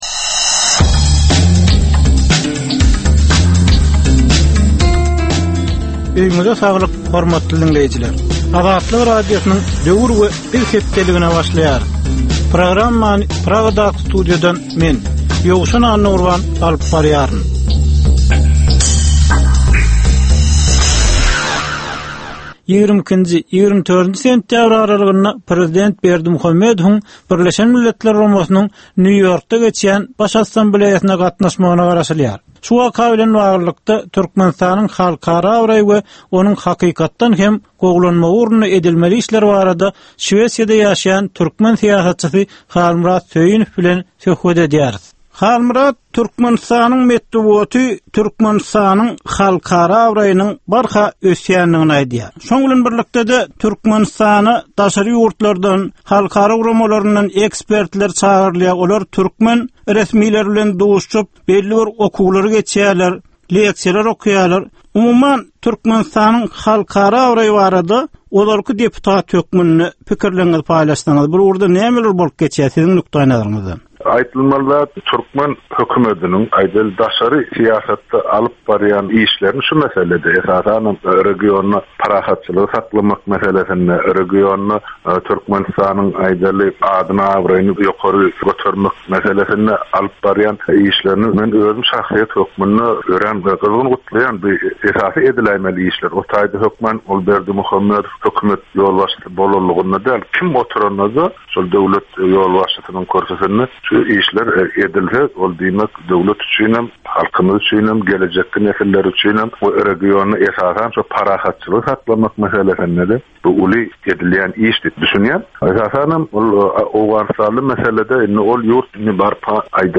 Türkmen jemgyýetindäki döwrüň meseleleri we döwrüň anyk bir meselesi barada ýörite gepleşik. Bu gepleşikde diňleýjiler, synçylar we bilermenler döwrüň anyk bir meselesi barada pikir öwürýärler, öz garaýyşlaryny we tekliplerini orta atýarlar.